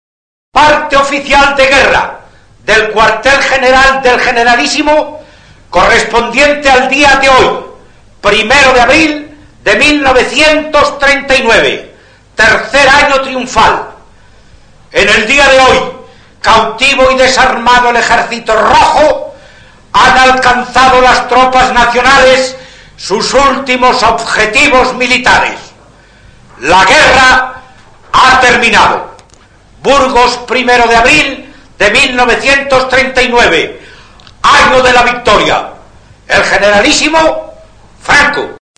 Parte Oficial de Guerra. 353 Kb.